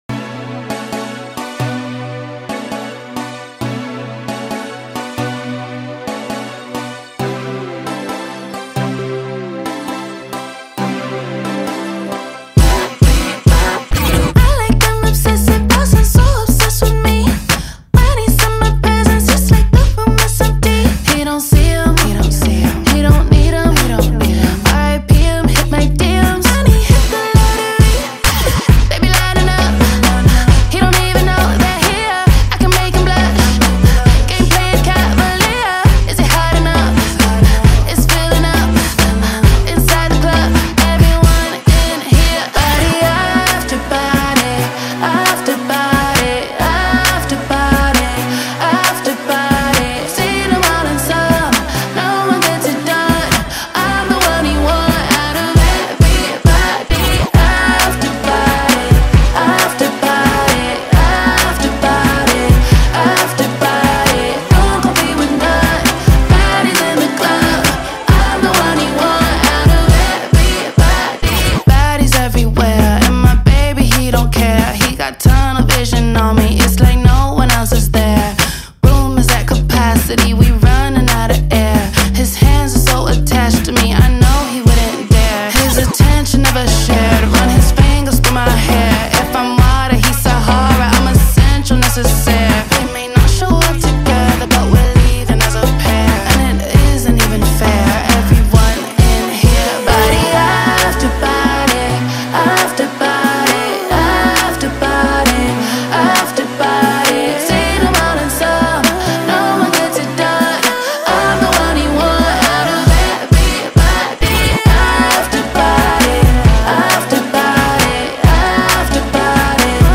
BPM134
Audio QualityPerfect (High Quality)